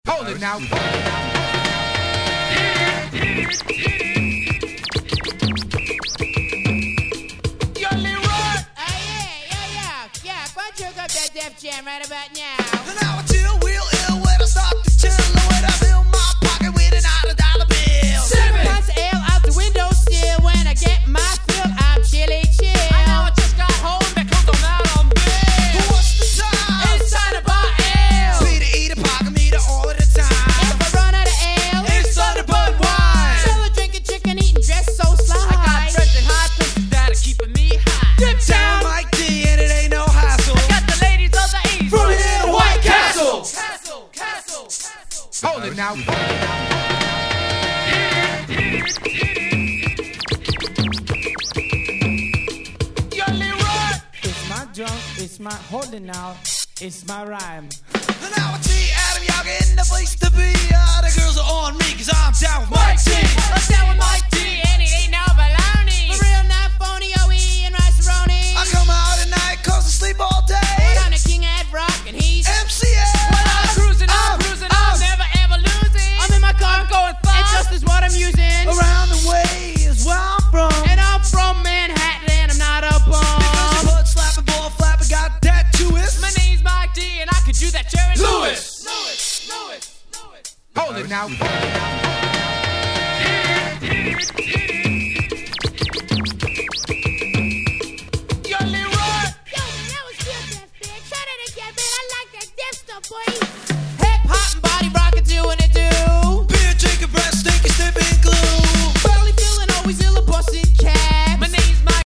Rock調の楽曲あり、ClassicなOld Schoolありの飽きの来ない最強の名盤！！